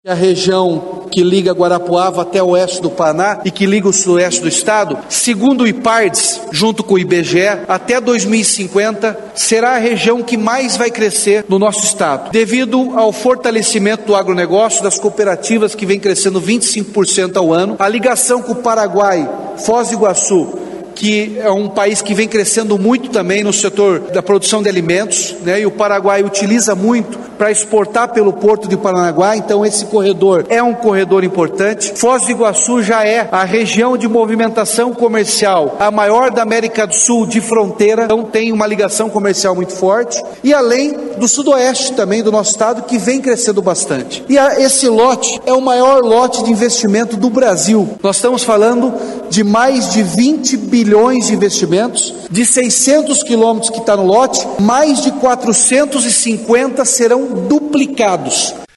Player Ouça Ratinho Júnior, governador do Paraná